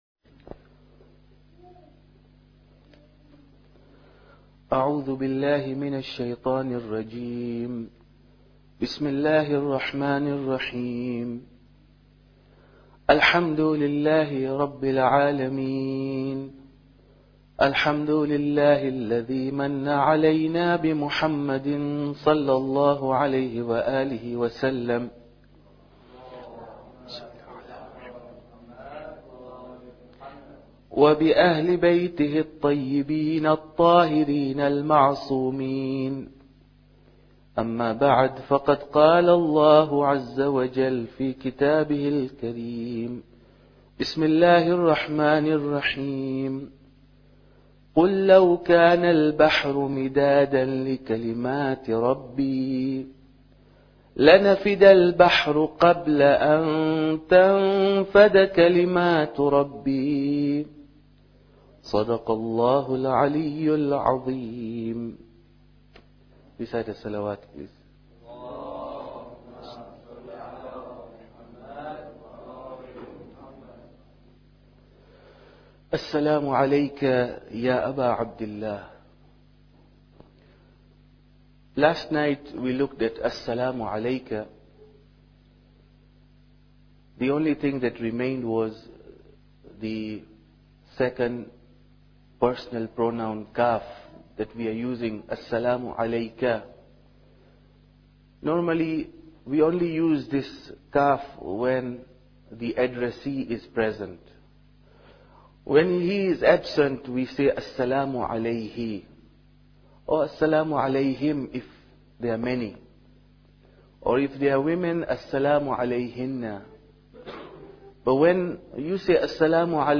Muharram Lecture 3